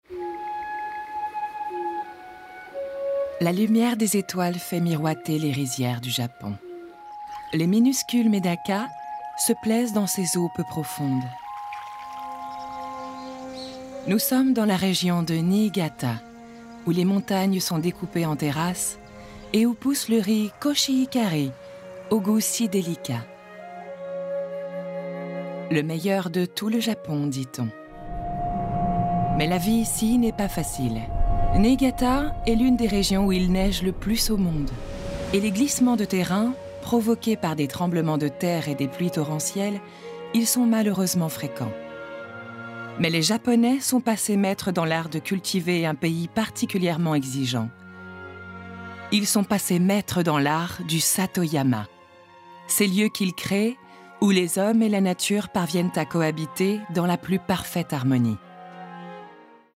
Pub
30 - 50 ans - Mezzo-soprano